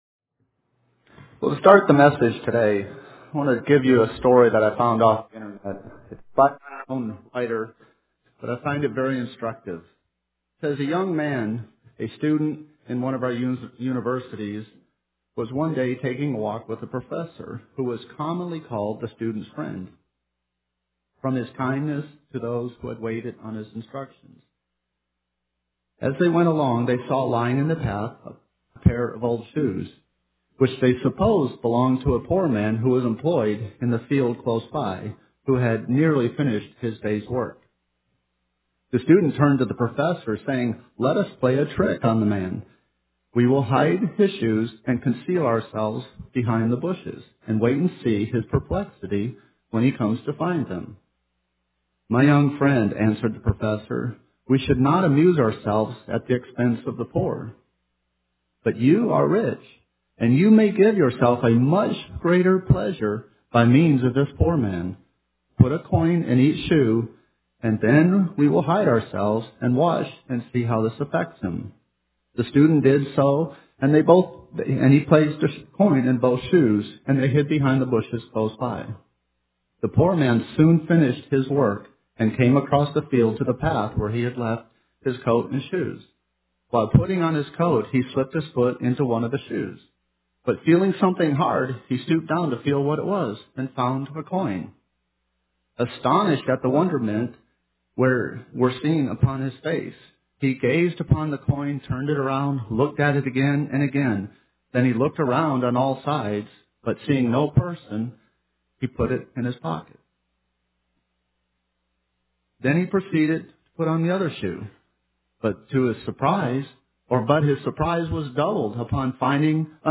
Given in Nashville, TN
UCG Sermon Studying the bible?